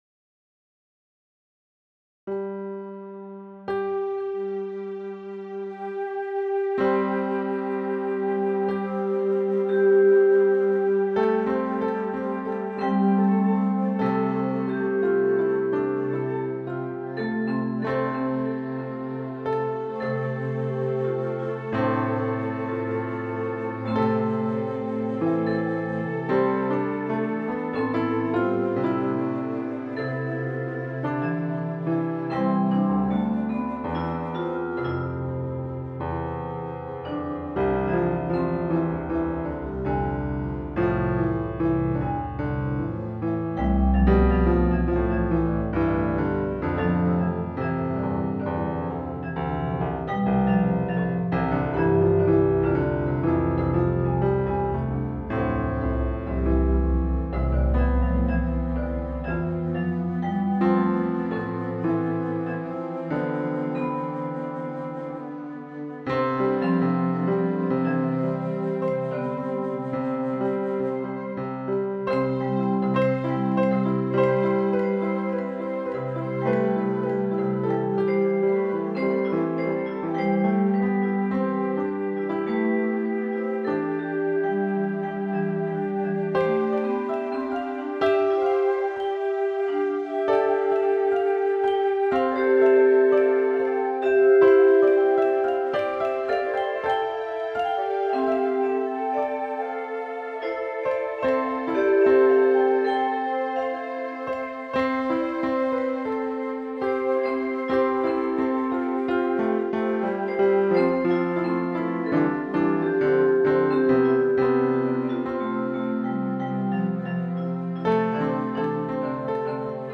Posted in Classical, Other Comments Off on